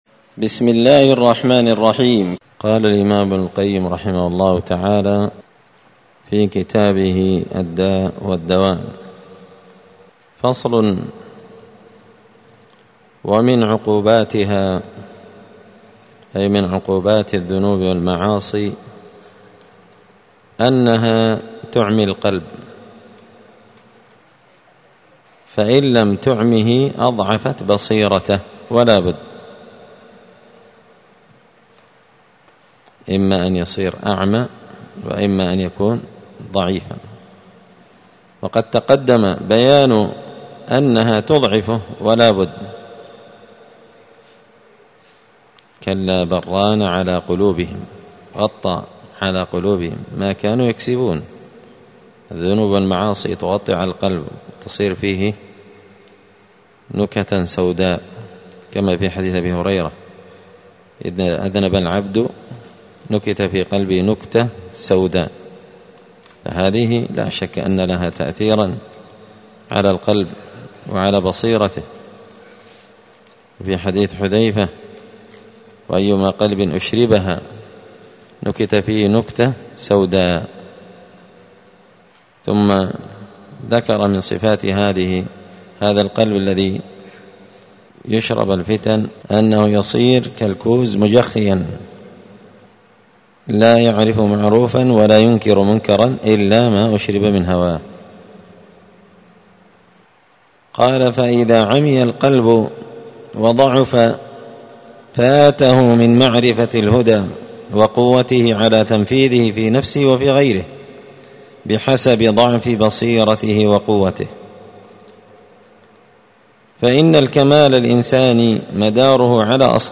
الجمعة 27 شعبان 1445 هــــ | الداء والدواء للإمام ابن القيم رحمه الله، الدروس، دروس الآداب | شارك بتعليقك | 30 المشاهدات
دار الحديث السلفية بمسجد الفرقان بقشن المهرة اليمن